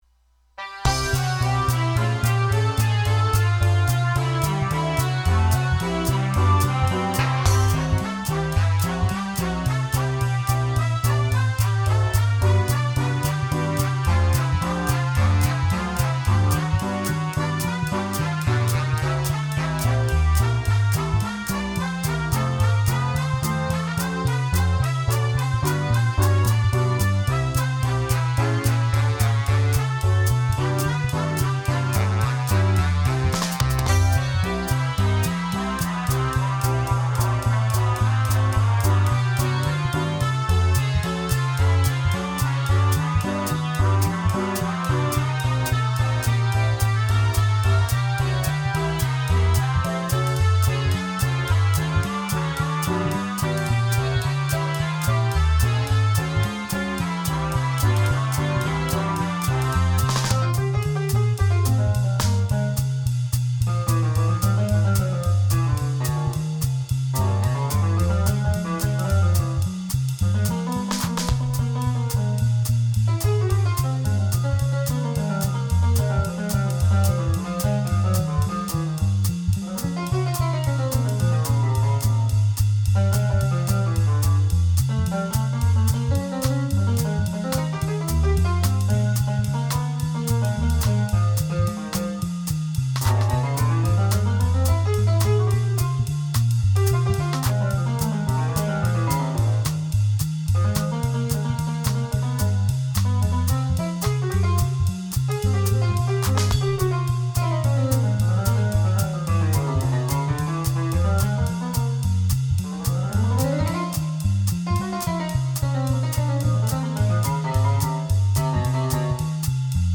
Both of them have 1930-50s jazz feel.